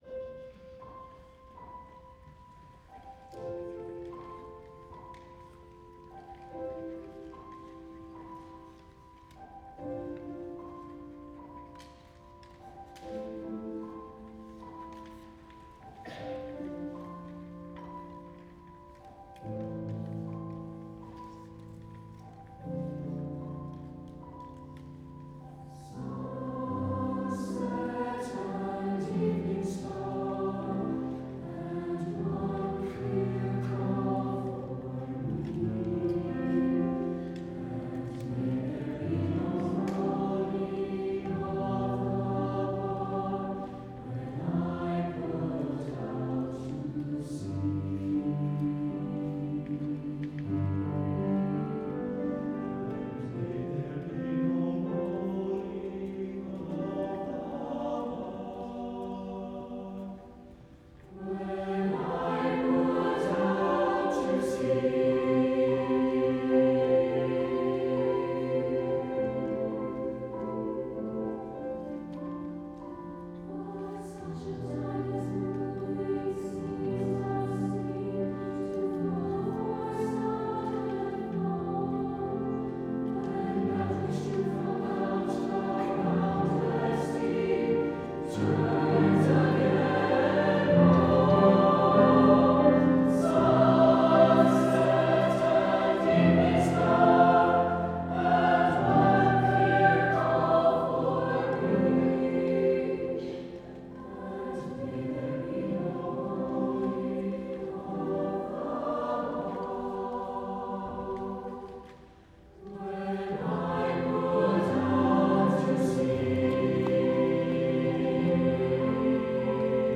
SATB and piano